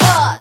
VEE Synth Voc 36.wav